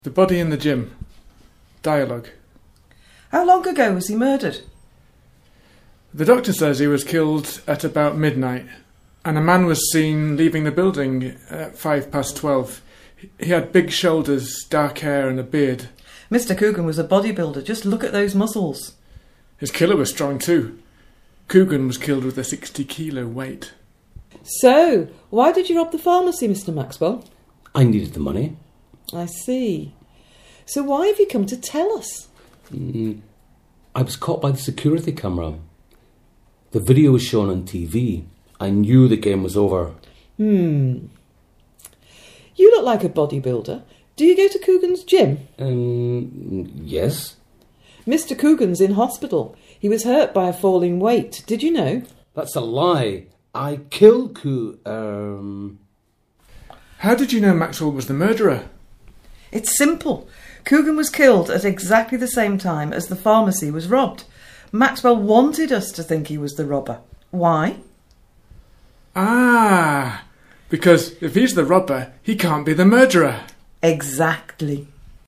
audio of dialogue1.15 MB
Body in the Gym Dialogue.mp3